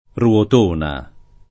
ruotona [ r U ot 1 na ]